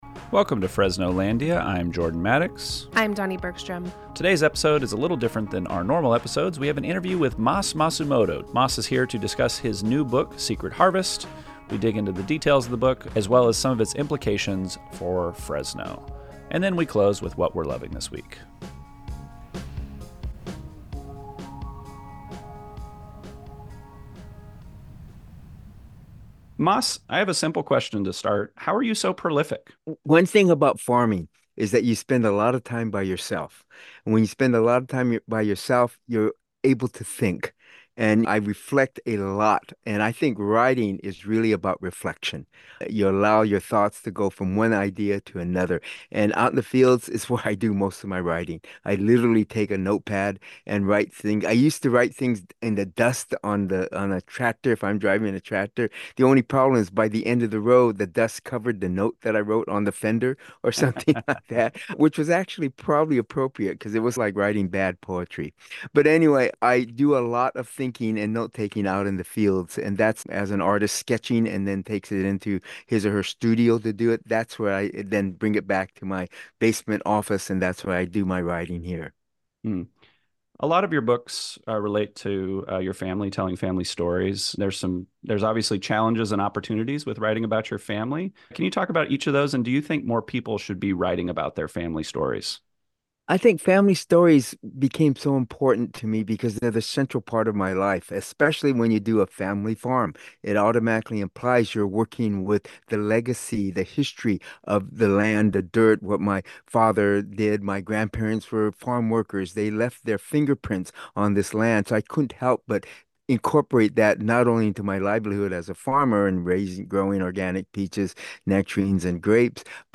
Book Club Interview with Mas Masumoto About His New Book Secret Harvest: A Hidden Story of Separation and the Resilience of a Family Farm